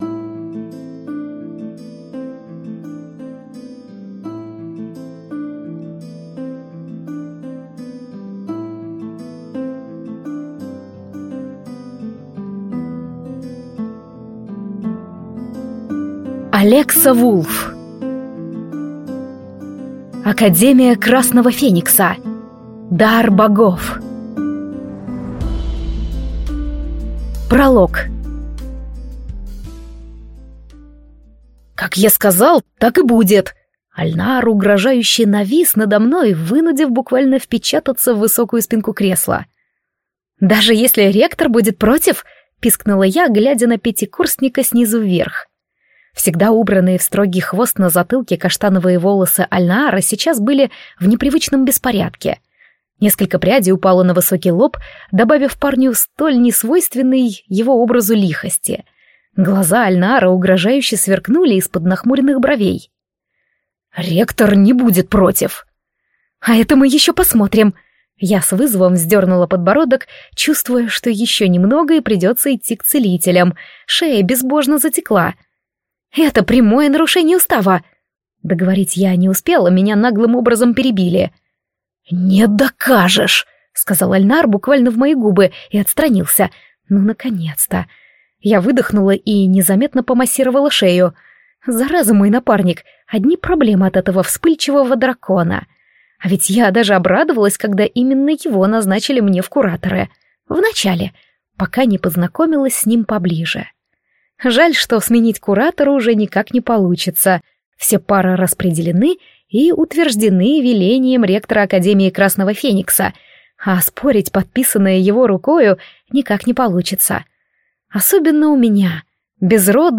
Аудиокнига Академия Красного Феникса. Дар богов | Библиотека аудиокниг